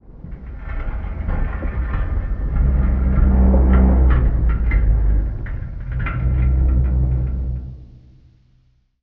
metal_low_creaking_ship_structure_06.wav